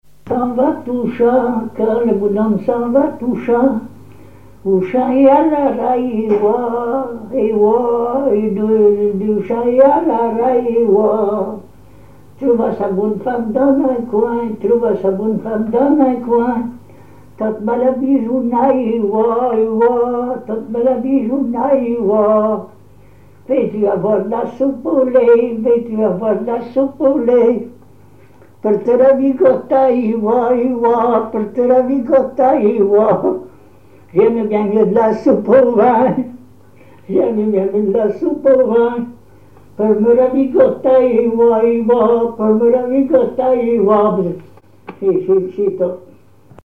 Genre laisse
instrumentaux à l'accordéon diatonique
Pièce musicale inédite